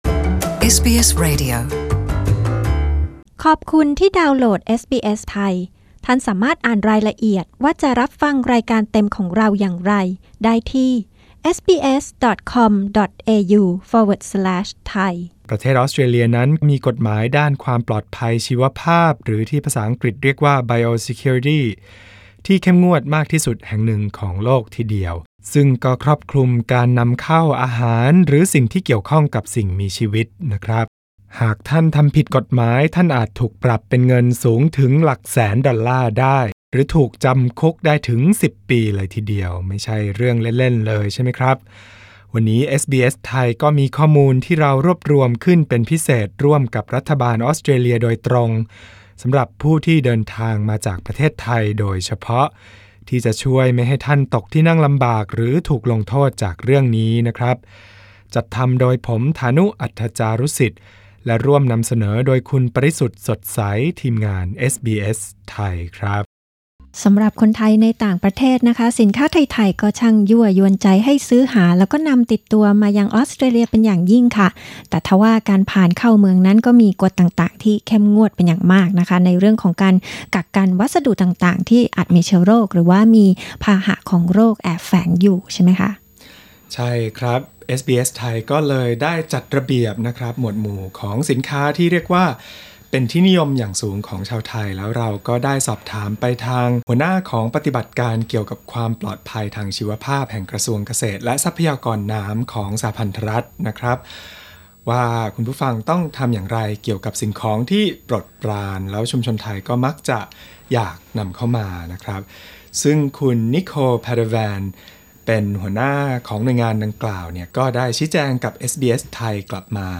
กดปุ่ม (▶) ด้านบนเพื่อฟังการพูดคุยรายละเอียดเรื่องนี้อย่างสนุกสนานเป็นภาษาไทย (19 นาที)